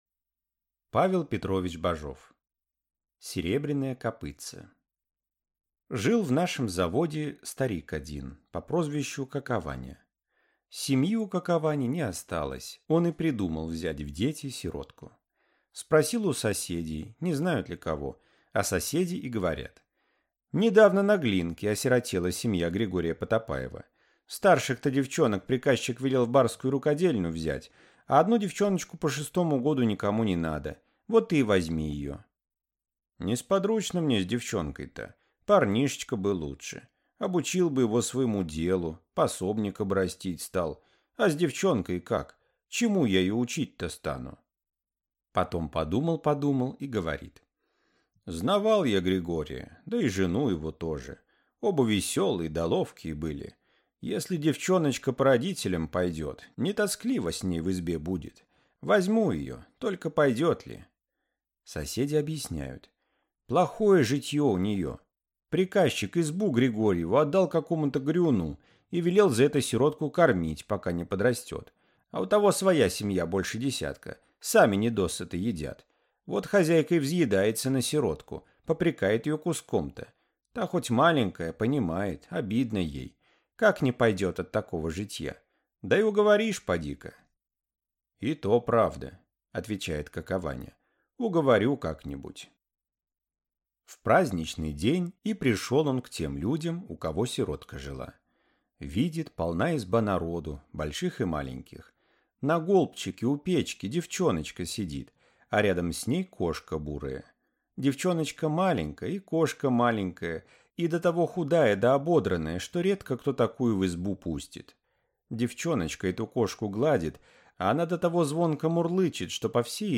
Аудиокнига Серебряное копытце | Библиотека аудиокниг